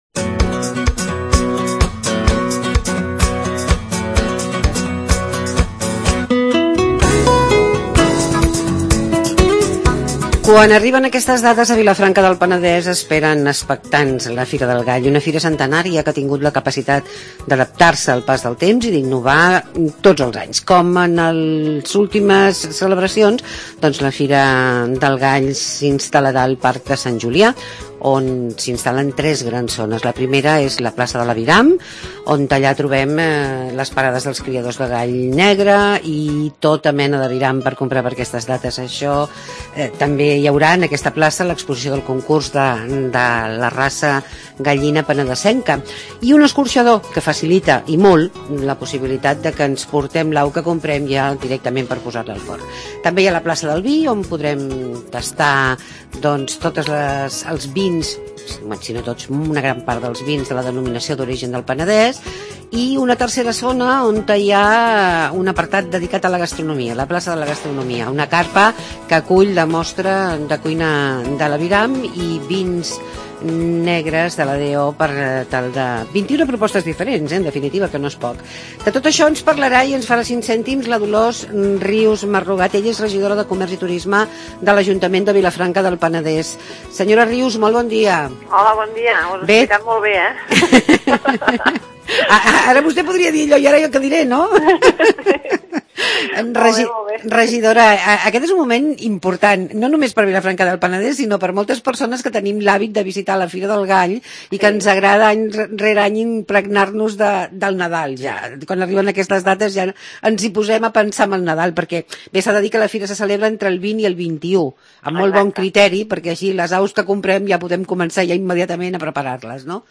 Dolors Rius, regidora de Comerç i Turisme de l'Ajuntament de Vilafranca del Penedés ens parla de la Fira del Gall. Una proposta de la Diputació de Barcelona.